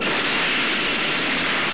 File: "Explosion #1" (Esplosione #1 )
Type: Sound Effect